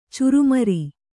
♪ curumari